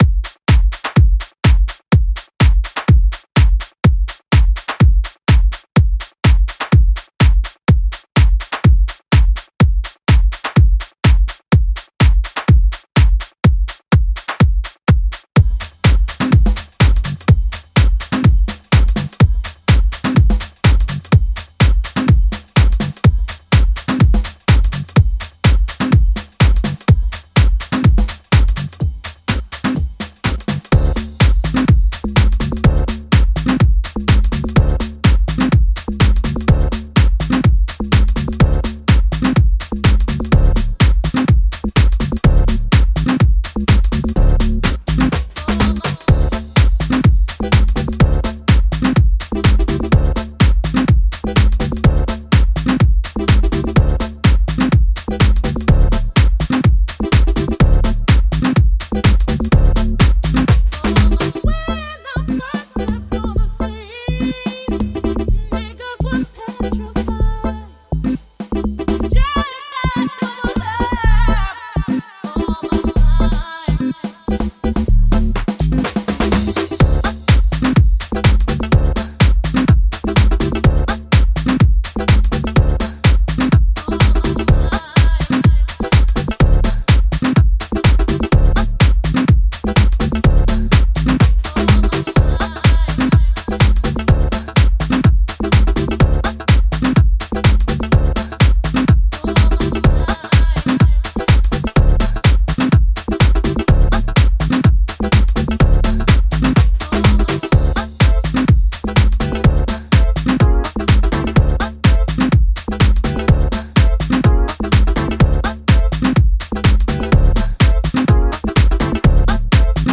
pure jacking house.